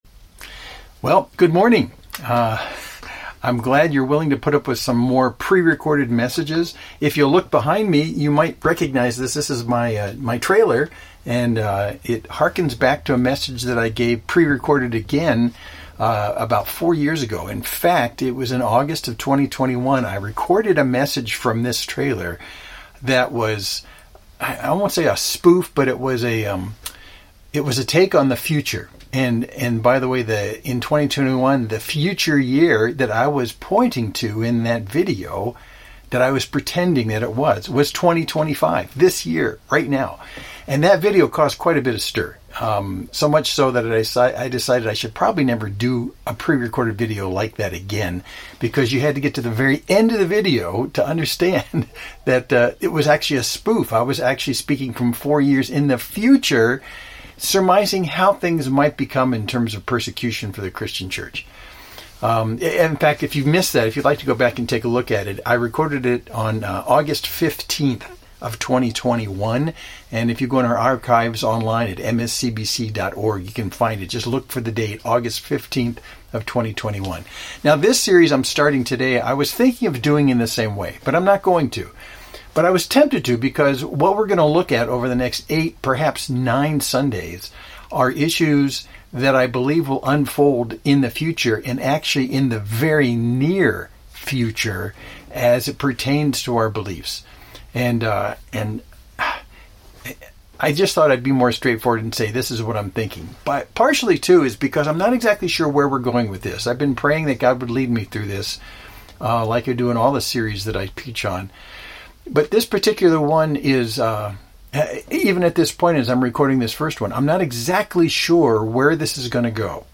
Main Street Church Sermon (17.38 - )